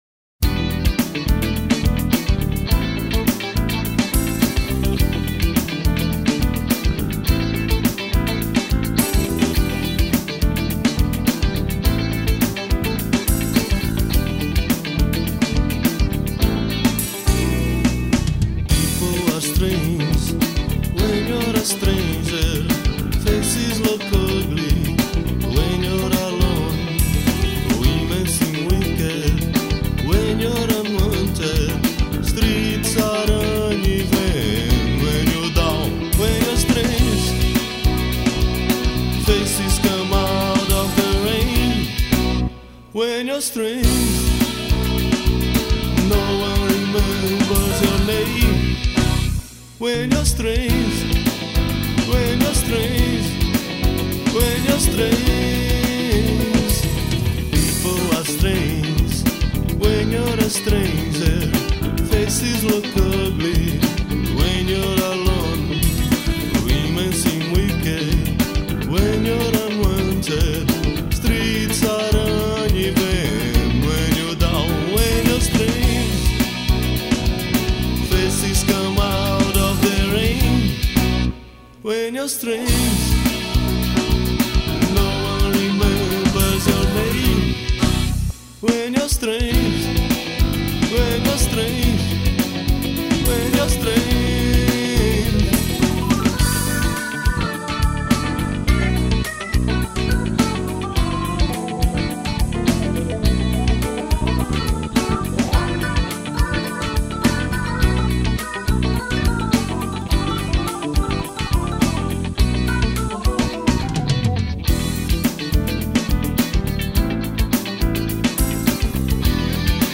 1041   04:07:00   Faixa:     Rock Nacional
Teclados